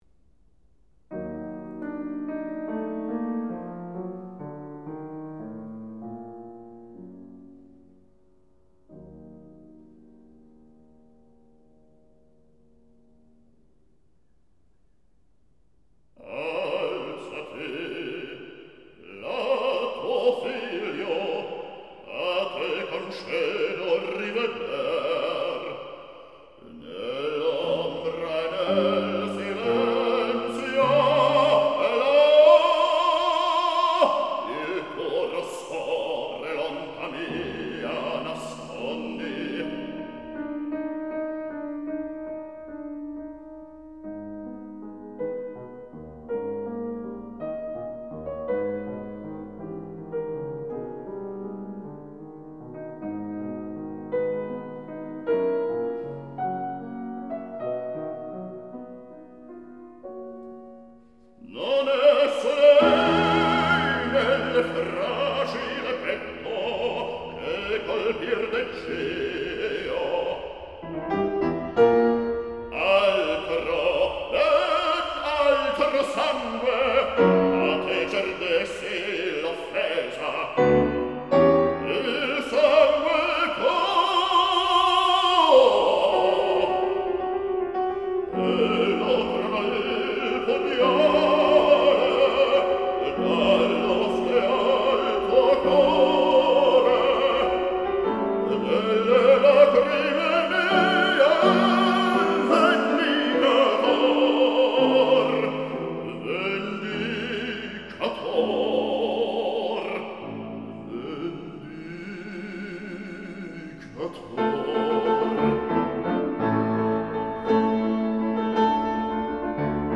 Heroic Baritone
The dramatic baritone